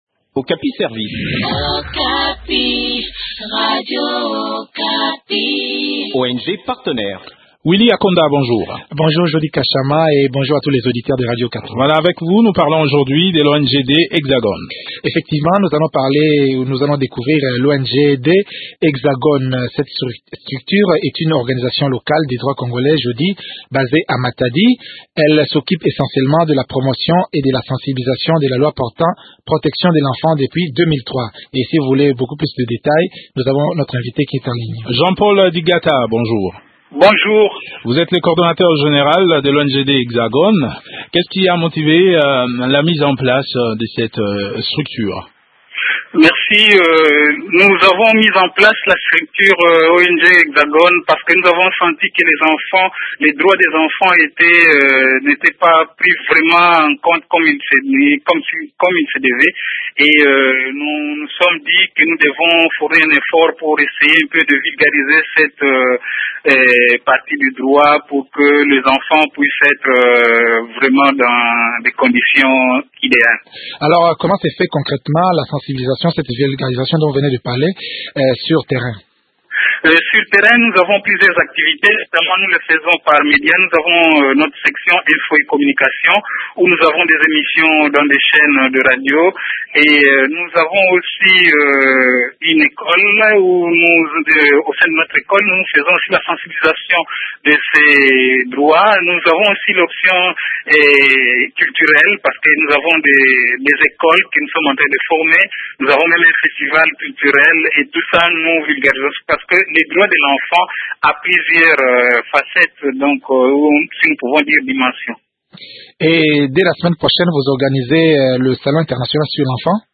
fait le point de leurs activités au micro de